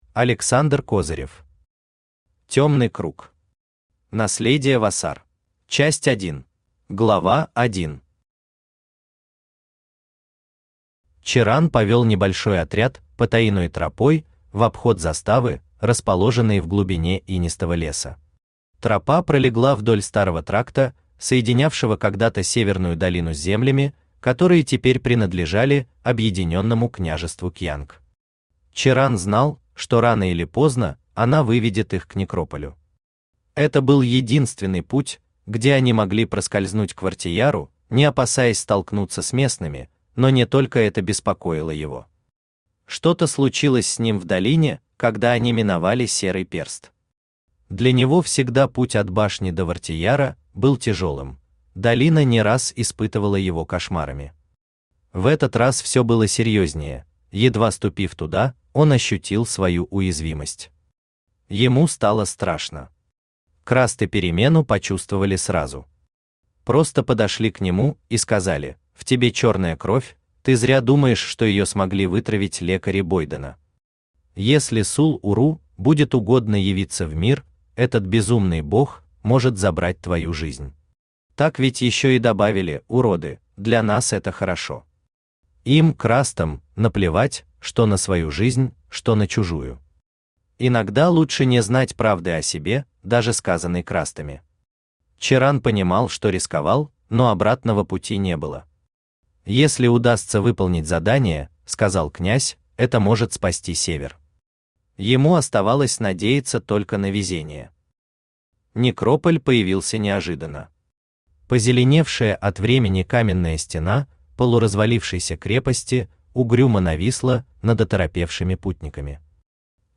Аудиокнига Темный круг. Наследие Вассар | Библиотека аудиокниг
Наследие Вассар Автор Александр Александрович Козырев Читает аудиокнигу Авточтец ЛитРес.